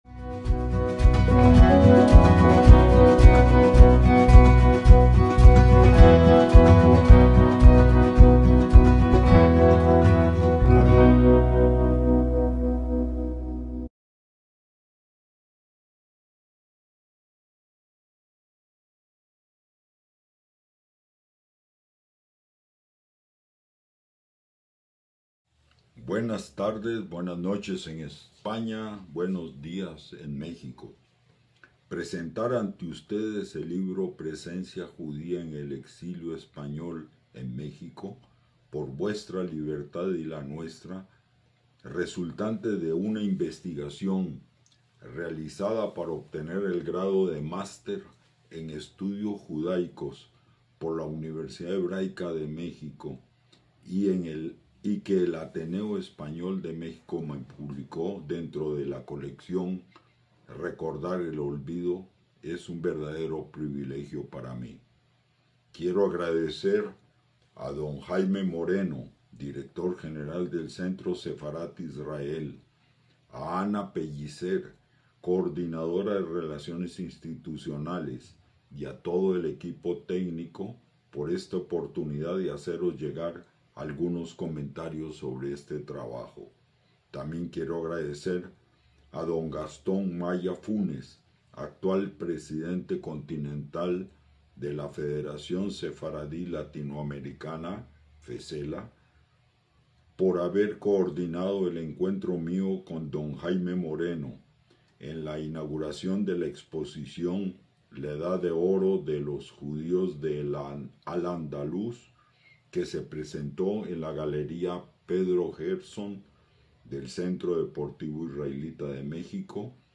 ACTOS "EN DIRECTO" - El 17 de abril de 2025 el Centro Sefarad Israel mantuvo una actividad telemática